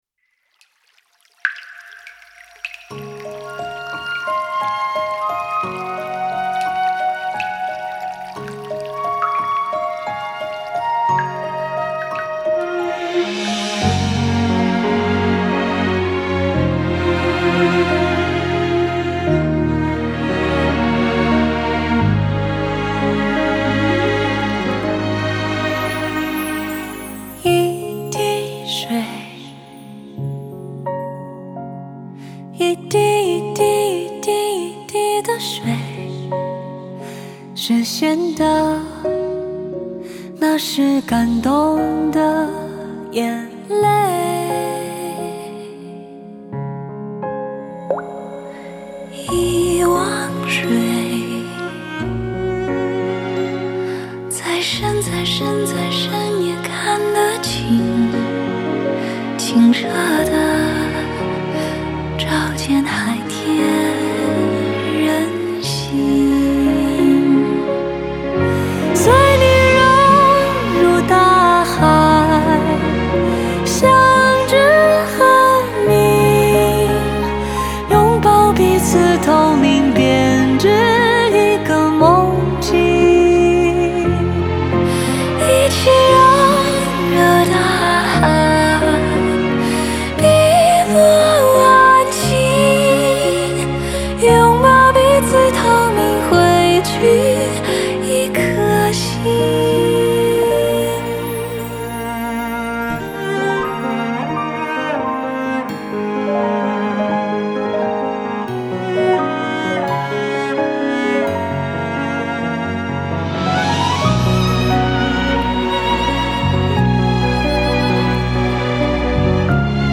旋律既有流水般的舒缓灵动，又暗藏海浪奔涌的力量感。